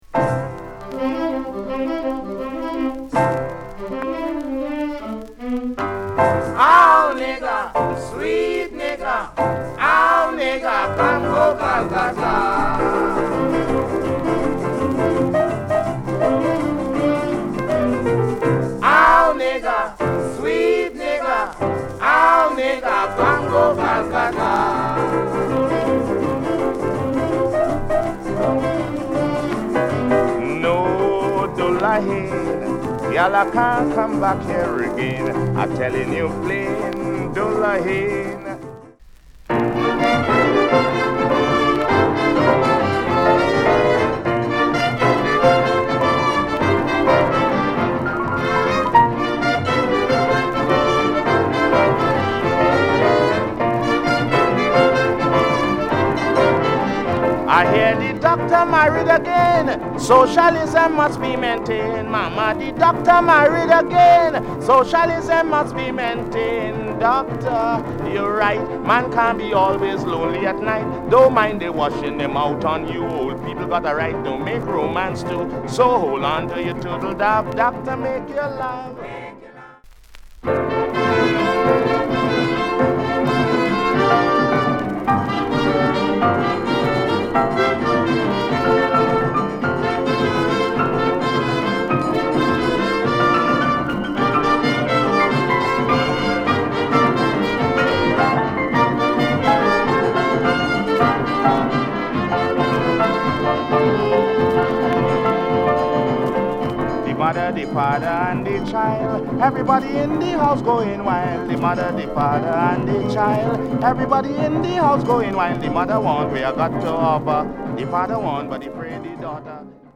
NICE CALYPSO